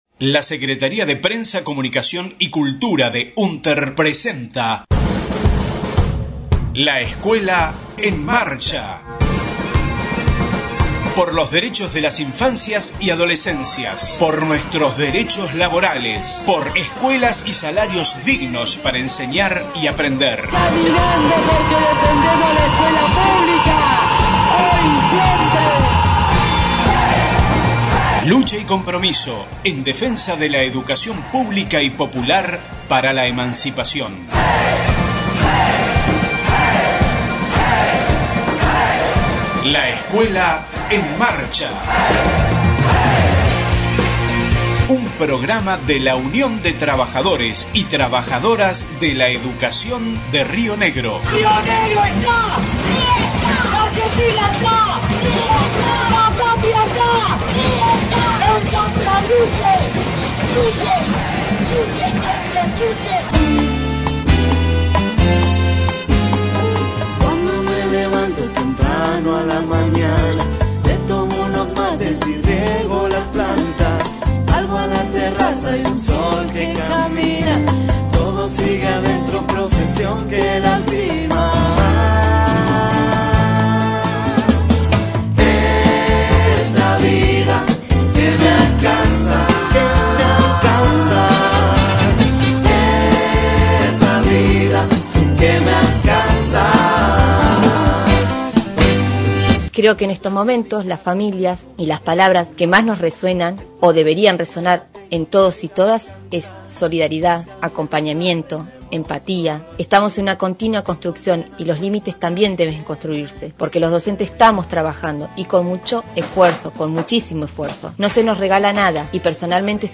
LEEM 08/05/20 Aislamiento, trabajo y familia, voces de docentes y directoras de la provincia.